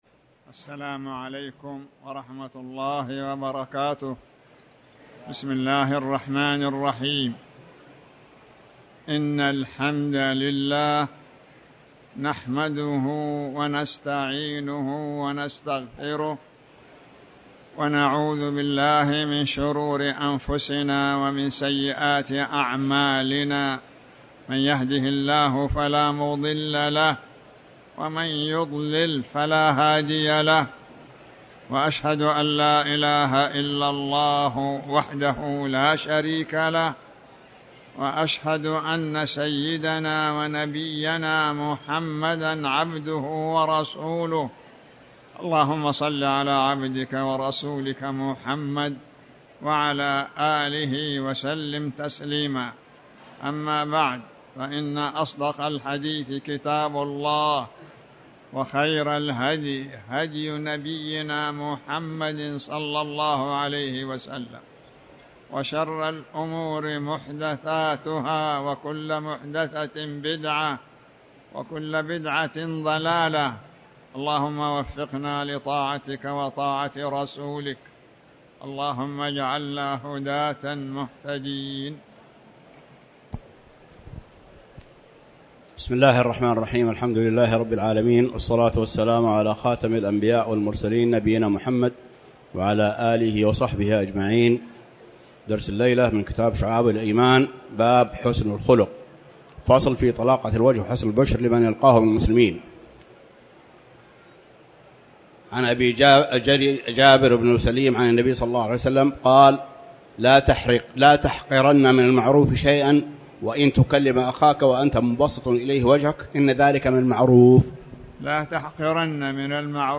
تاريخ النشر ٦ ذو القعدة ١٤٤٠ هـ المكان: المسجد الحرام الشيخ